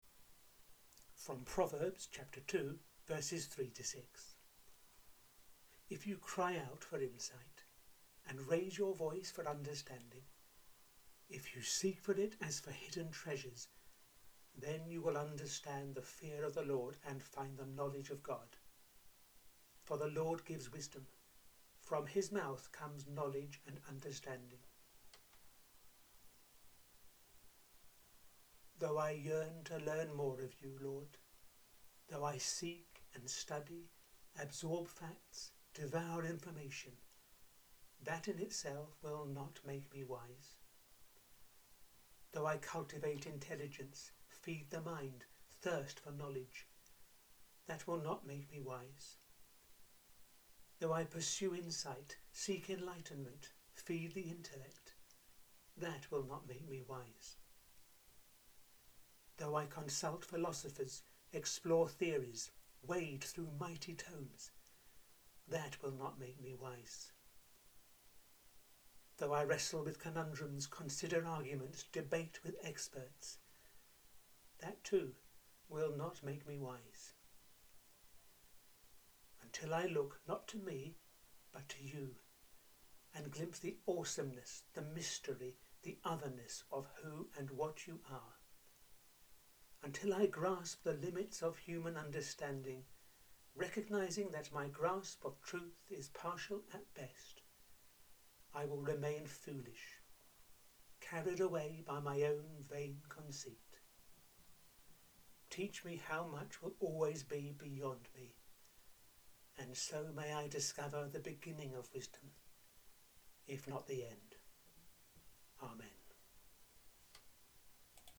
The seventeenth prayer in the audio series I’m running over these few weeks: